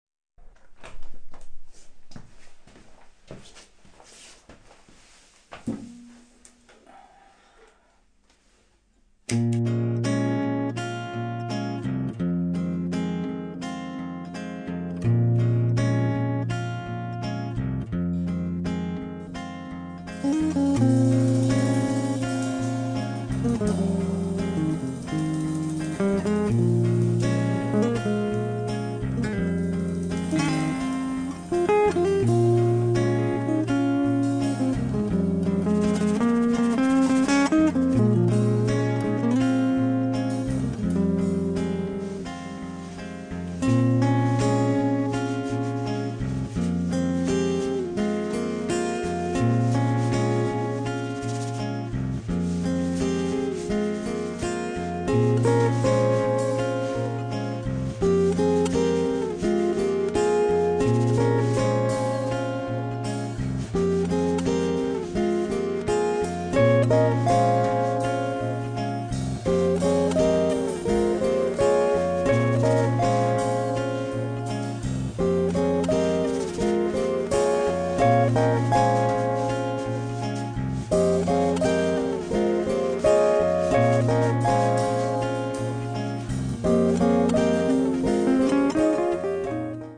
chitarra
batteria e percussioni